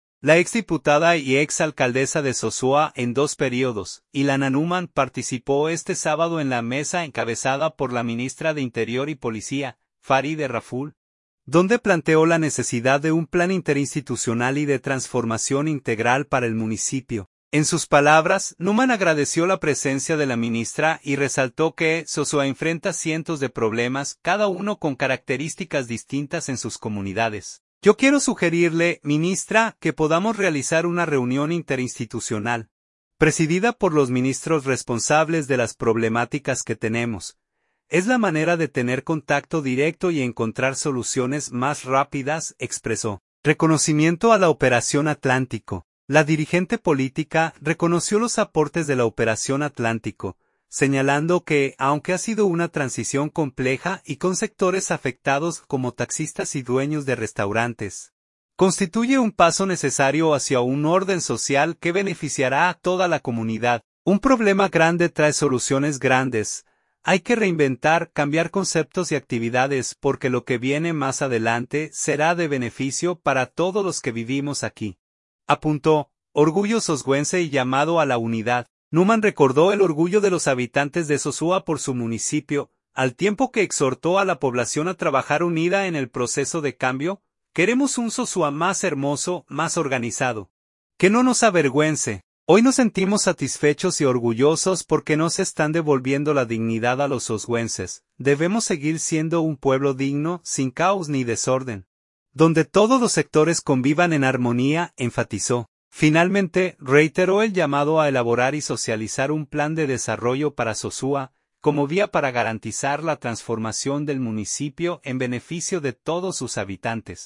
Sosúa, Puerto Plata.– La ex diputada y ex alcaldesa de Sosúa en dos períodos, Ilana Neumann, participó este sábado en la mesa encabezada por la ministra de Interior y Policía, Faride Raful, donde planteó la necesidad de un plan interinstitucional y de transformación integral para el municipio.